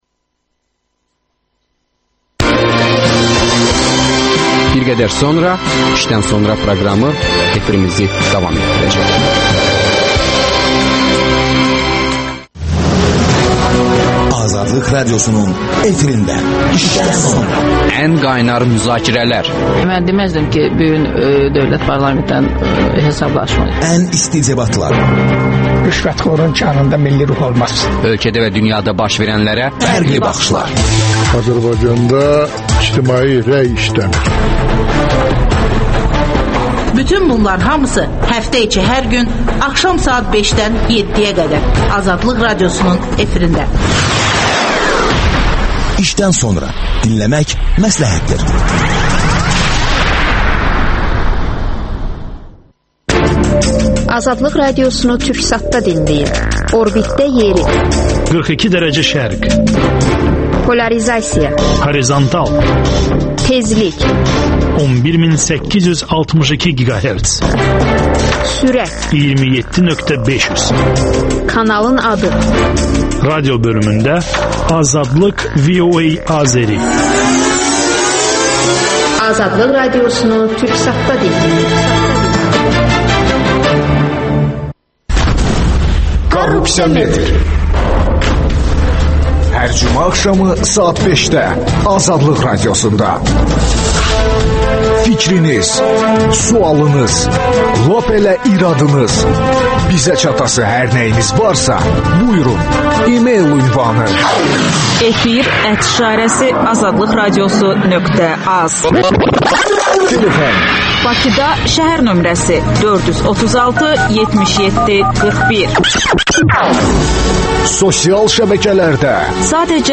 İşdən sonra - TQDK rəsmisi canlı efirdə...